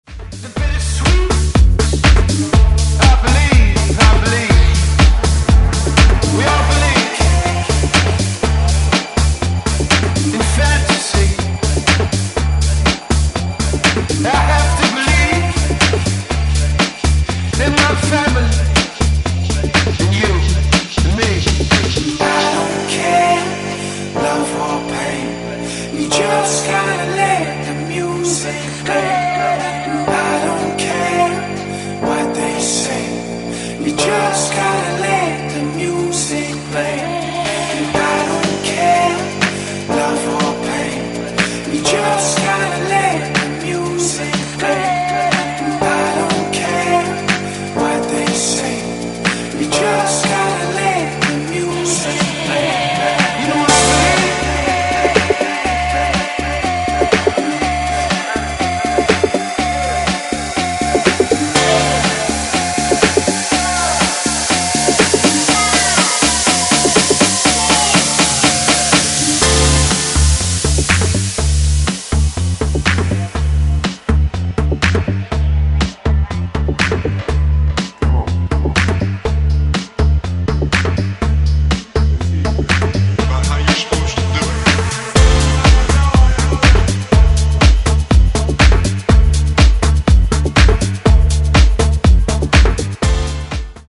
ジャンル(スタイル) NU DISCO / DISCO / HOUSE